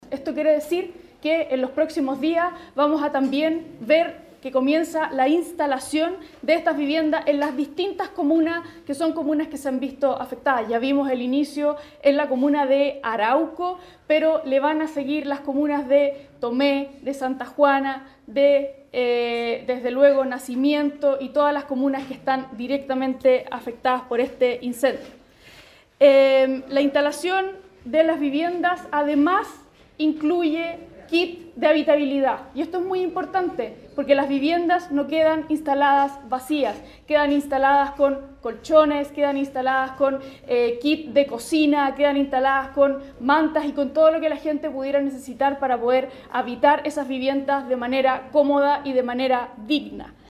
Durante el mismo balance, la delegada presidencial, Daniel Dresdner, indicó que “a la fecha hay 1.119 viviendas destruidas por el fuego y aún quedan 500 por evaluar”.